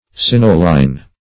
Search Result for " cinnoline" : The Collaborative International Dictionary of English v.0.48: Cinnoline \Cin"no*line\, n. [Cinnamic + quinoline.] A nitrogenous organic base, C8H6N2 , analogous to quinoline, obtained from certain complex diazo compounds.
cinnoline.mp3